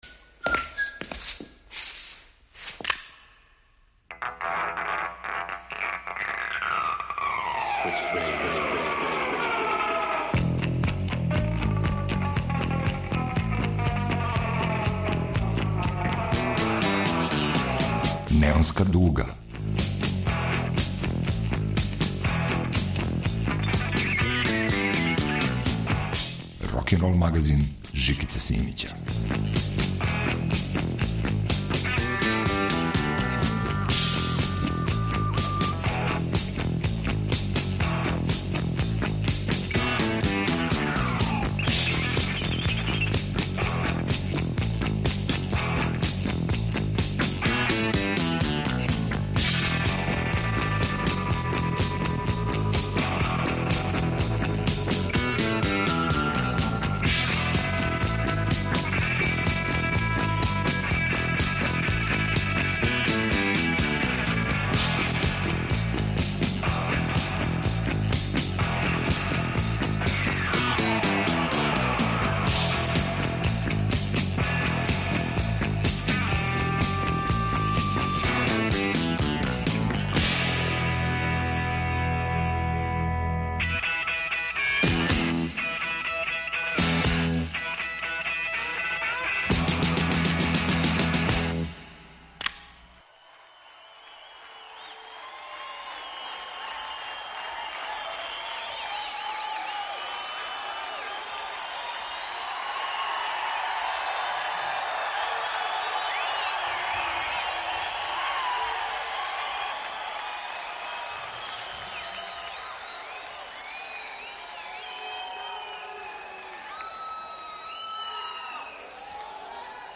Слушајте необуздани ток рокерске свести у 11 песама: надреално и свакодневно, урбано и рурално, егзотично и прозаично, бизарно и апсурдно у ритму јужњачког бугија, Њу Орлеанса, кантри рока и чикашког блуза.